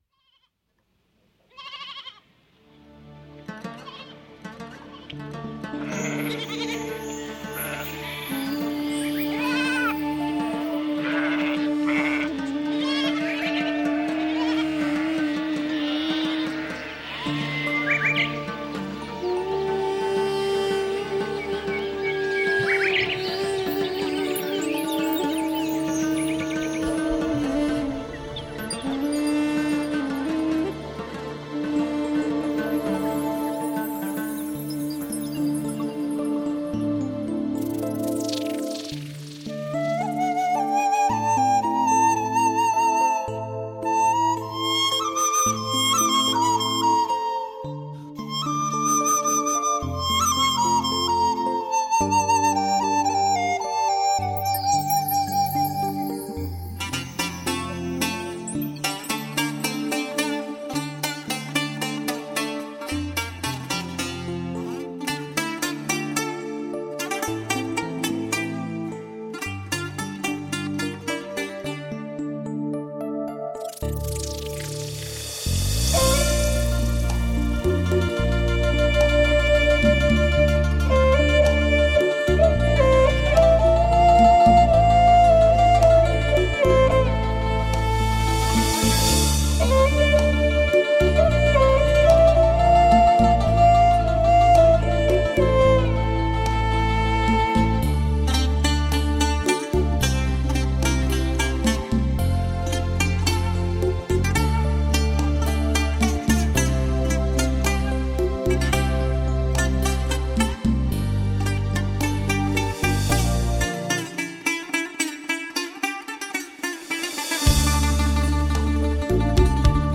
采用纯正新疆民族乐器如萨它尔、艾捷克、热瓦普、都它尔等，由资深新疆民族音乐人精心演奏，
力图为您重现悠扬婉转，别具风情的民族音乐画卷，将您带入大漠孤烟、长河落日的壮美西域风光之中。